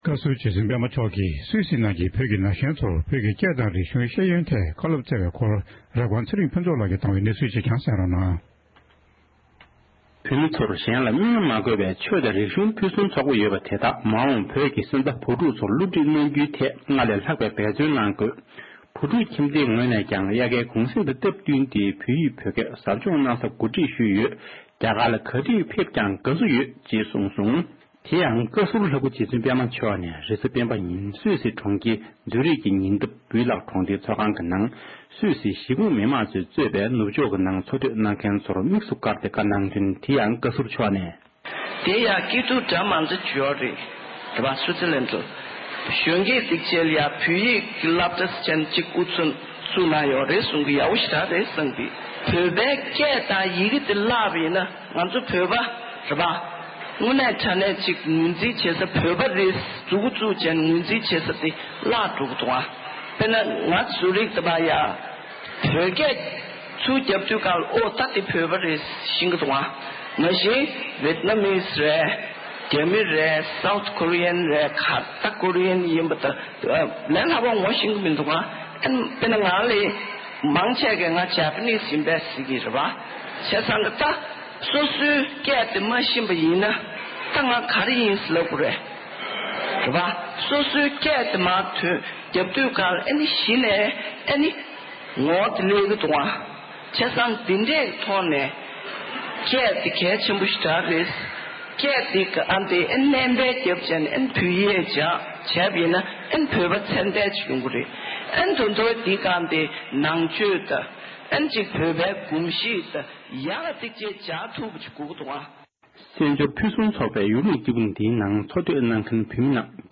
སྐུའི་གཅུང་མོ་རྗེ་བཙུན་པདྨ་མཆོག་ནས་སུད་སི་བོད་རིགས་ན་གཞོན་ཚོར་བཀའ་སློབ་སྩོལ་བཞིན་པ།
སྒྲ་ལྡན་གསར་འགྱུར།